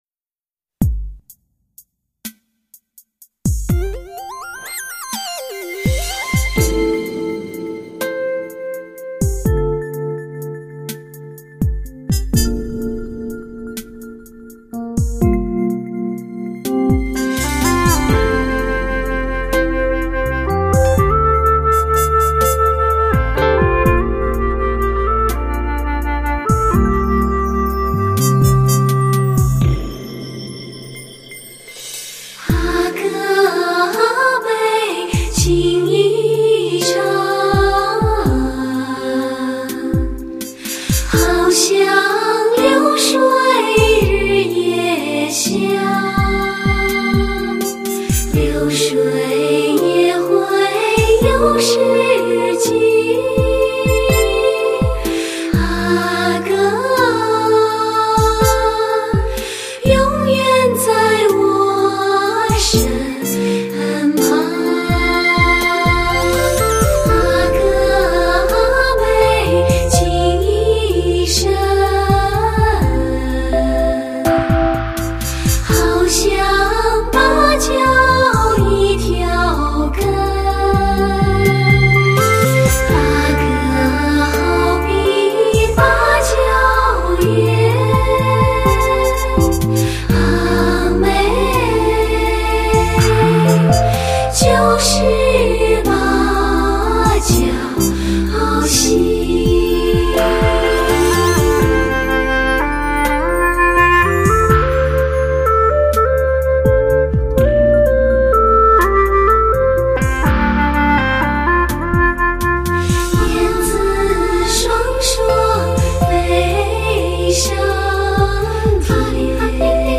极至感性恬静甜美发烧女声，征服你的耳朵，当你聆听她的时候，就这样容易的醉了。。。